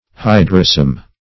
Search Result for " hydrosome" : The Collaborative International Dictionary of English v.0.48: Hydrosome \Hy"dro*some\, Hydrosoma \Hy`dro*so"ma\, n. [NL. hydrosoma.